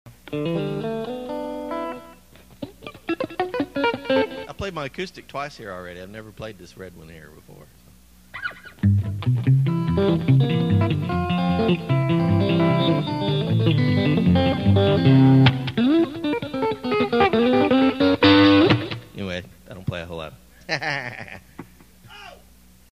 On September 23, 2000 a little church at the base of Redtop Mountain in Acworth, Georgia decided to use the 11 acres God had blessed them with to host an all-day outdoor Christian music festival.
This is a PA soundboard recording, using inferior quality cassette tapes. The performers were unaware that they were being recorded, there were some technical difficulties, and it did rain some that day.